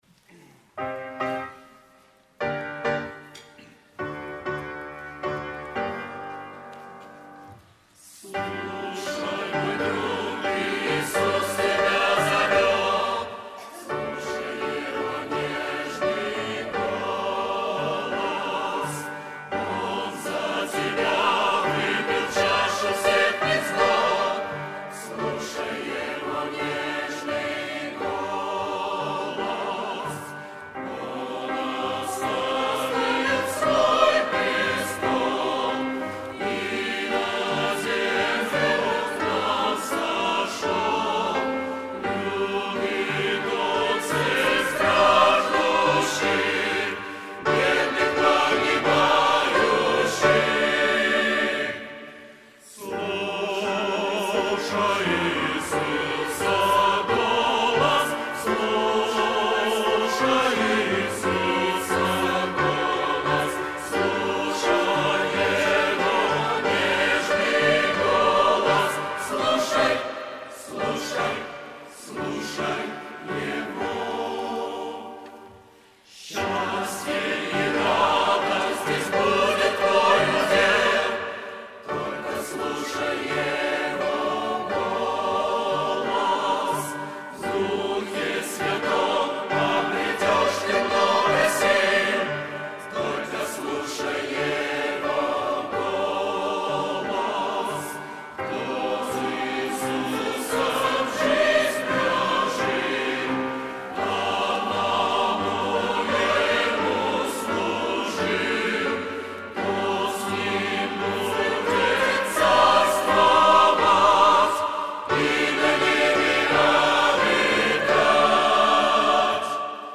Богослужение 06.10.2024
Слушай, мой друг - Хор (Пение)[